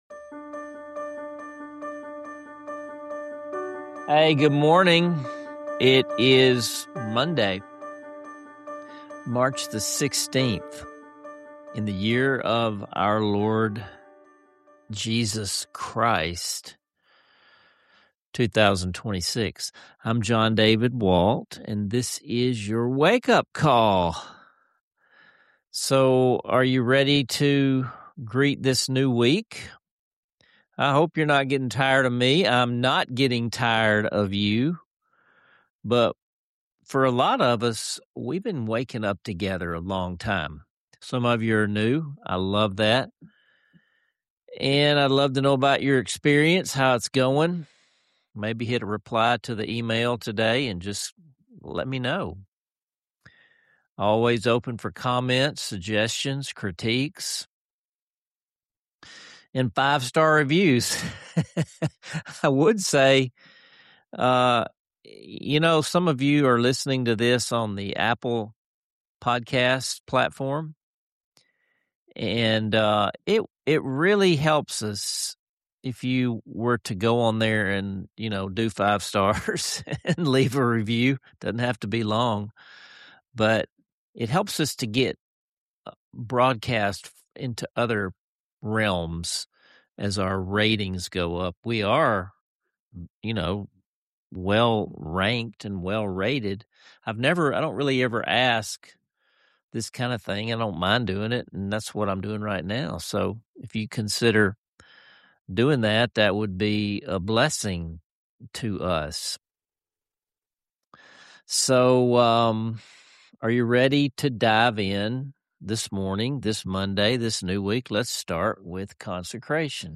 Key moments you don’t want to miss: The creative “flux capacitor” metaphor and how it reframes our understanding of human identity and spiritual capacity. A deeply personal conversation about being lost and found—not just in the woods, but in life—and how amazing grace changes us day by day.